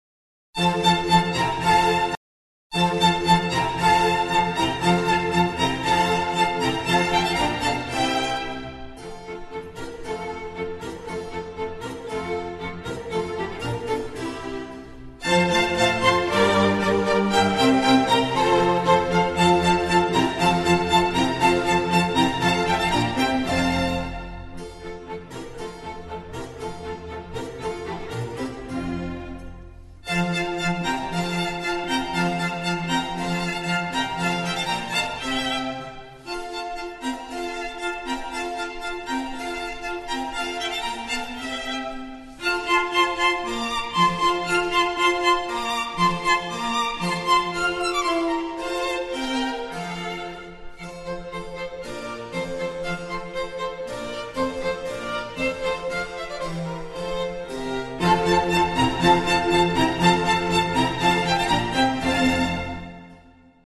Програмова музика – це інструментальні твори, які мають словесну програму або назву.